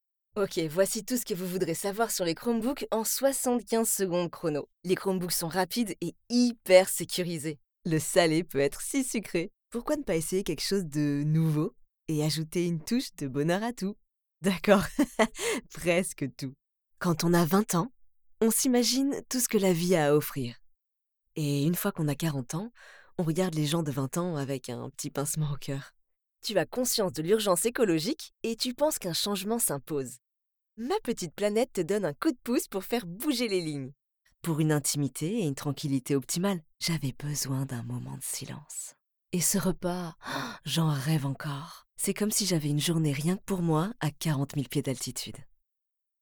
Voix off
Ma voix se situe dans les médiums, médiums-graves mais je peux également aller chercher la petite note fluette et aigüe qui illustre si bien nos jeunes années.
De plus, je parle couramment l'anglais (j'ai vécu 7 ans à New York) et je dispose d'un home studio professionnel qui me permet d'enregistrer à distance.